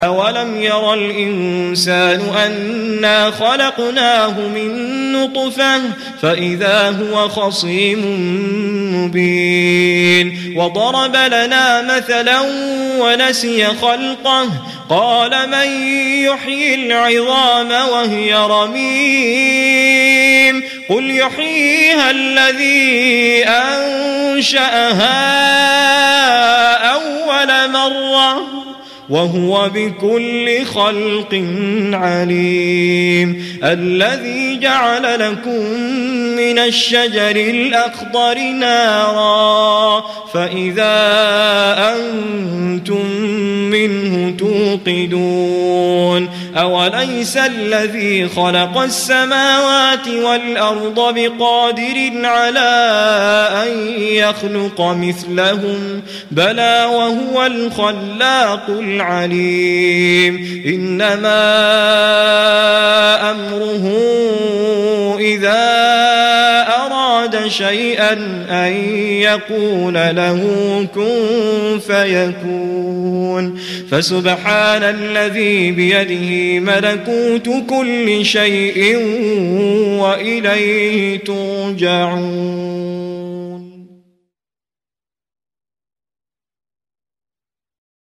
القارىء: توفيق الصائغ